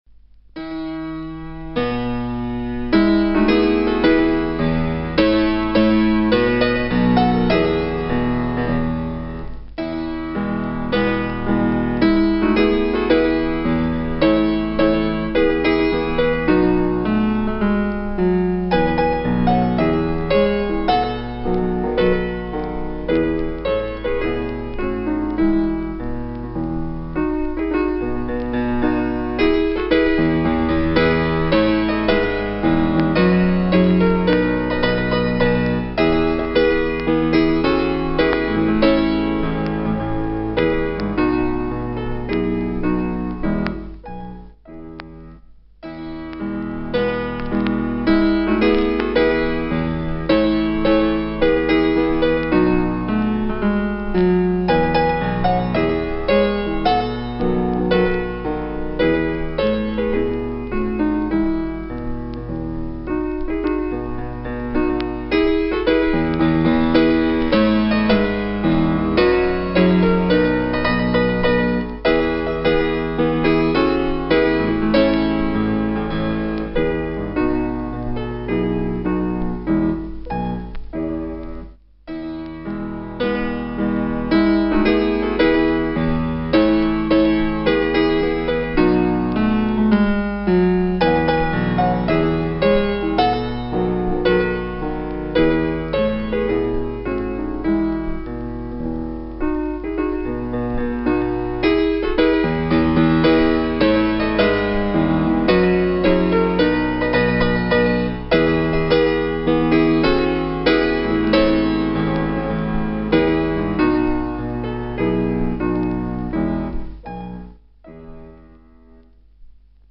木更津市民歌（ピアノ伴奏のみ） （mp3） (音声ファイル: 4.7MB)